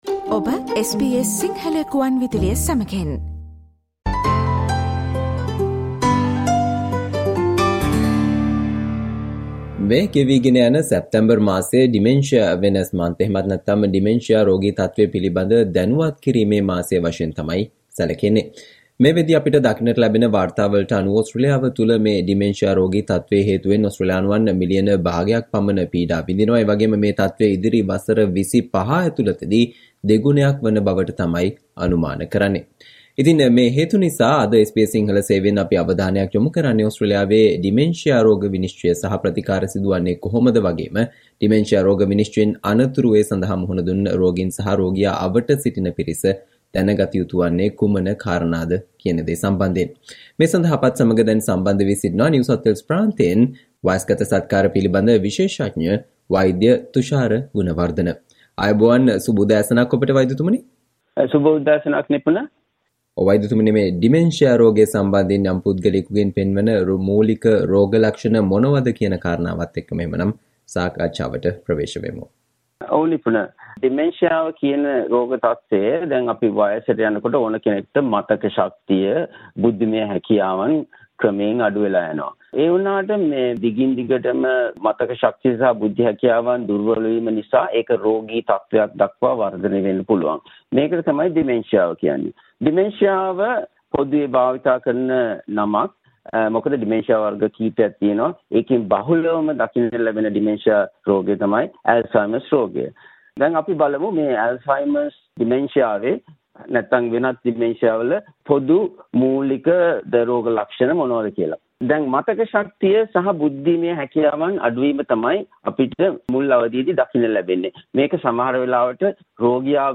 Listen to the SBS Sinhala discussion on Things people with dementia and their carers should know